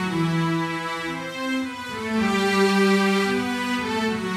Index of /musicradar/80s-heat-samples/110bpm
AM_80sOrch_110-E.wav